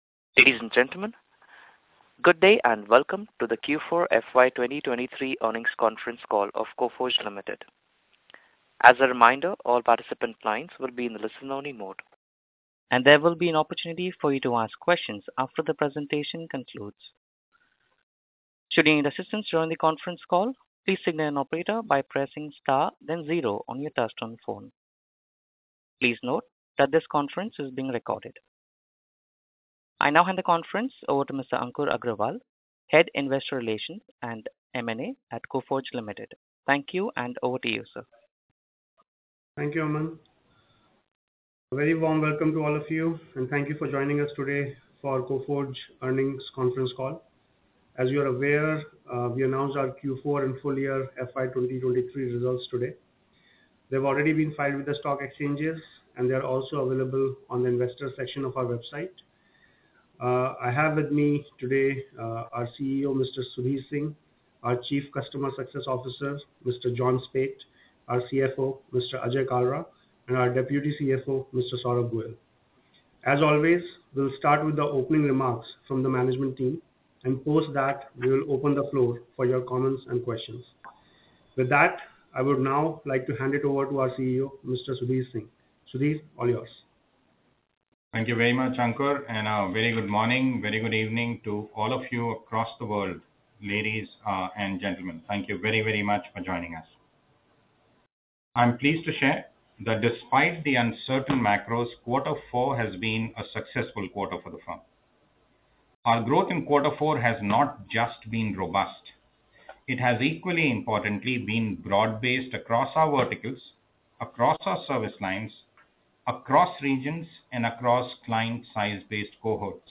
Earnings Conference Call audio